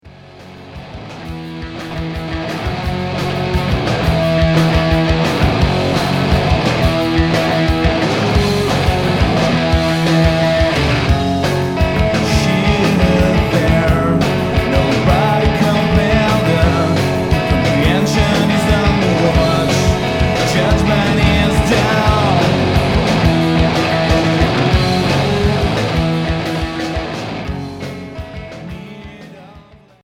Hippy version
Noisy rock